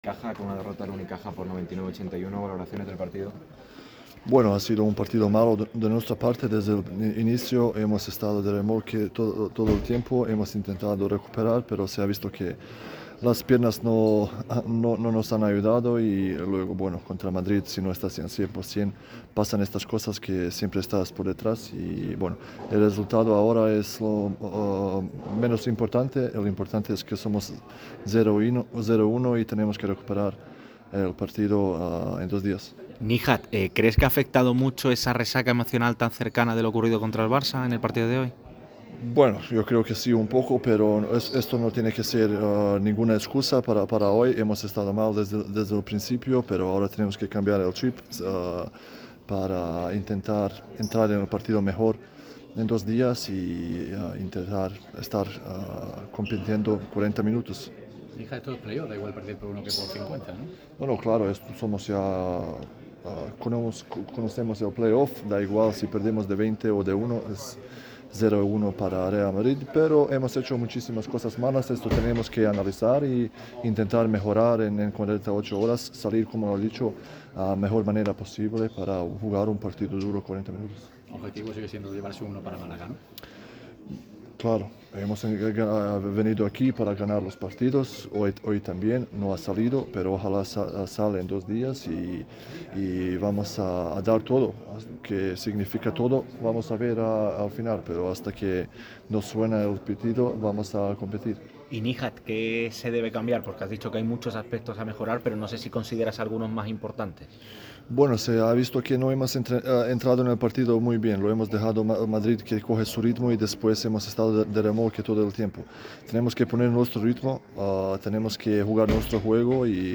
El bosnio pasó por zona mixta tras la derrota de los cajistas en el primer partido de las semifinales. El Unicaja fue superado con contundencia por un Real Madrid muy enchufado, que no dio oportunidad a la vuelta de tortilla.